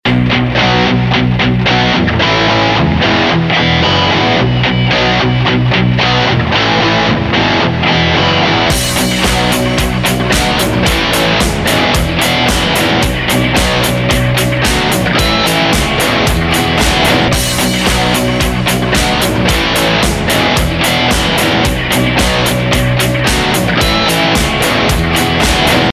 Мощный проигрыш